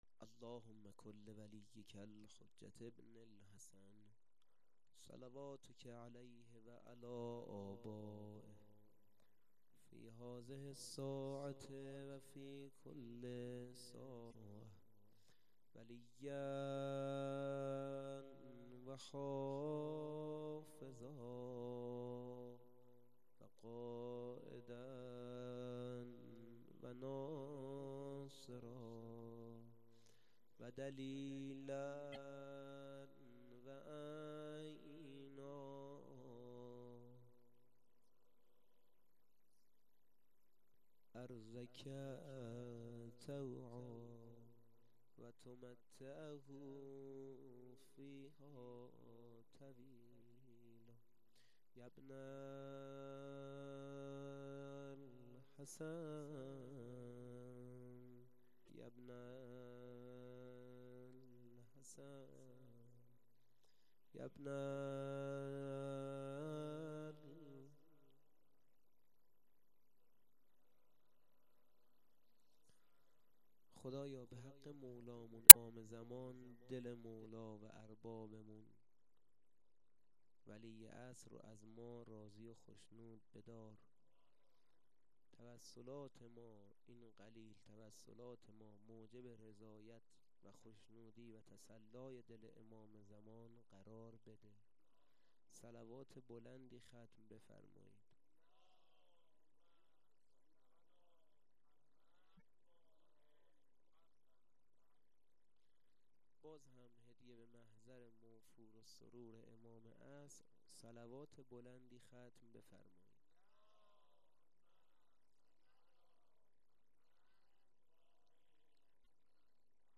2-sokhanrani.mp3